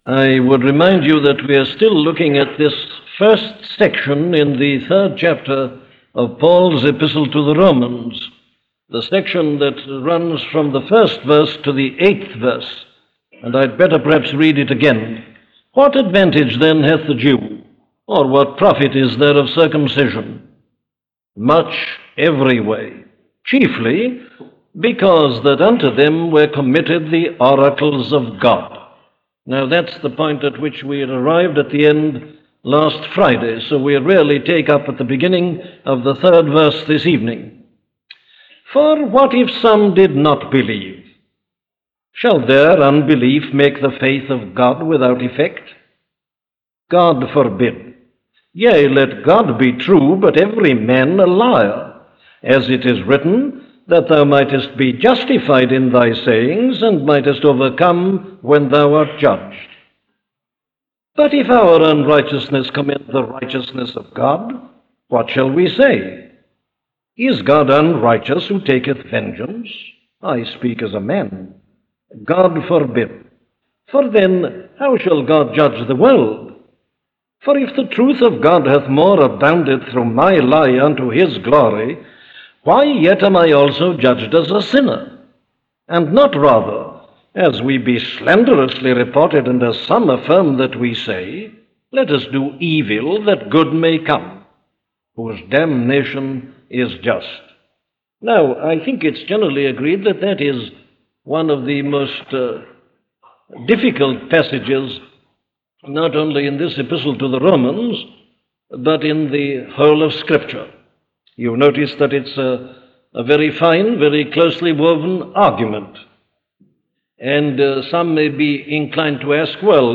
A collection of sermons on Sermons on Faith by Dr. Martyn Lloyd-Jones